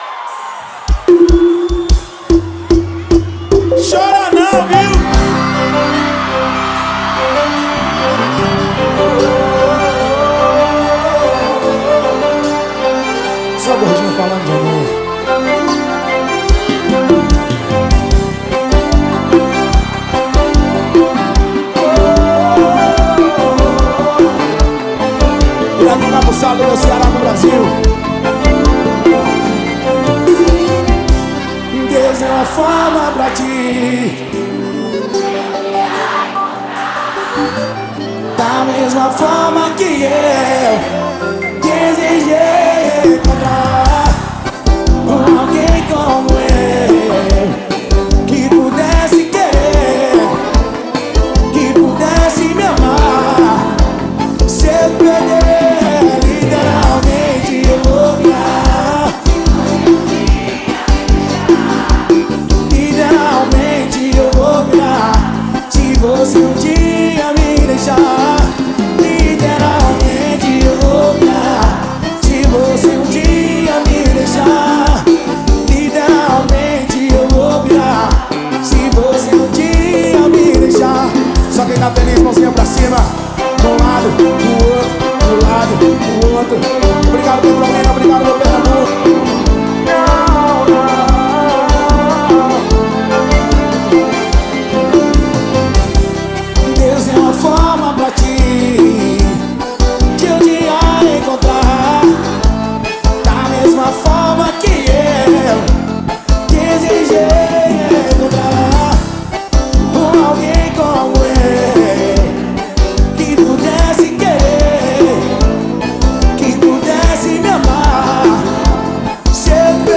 2025-01-23 11:33:00 Gênero: Forró Views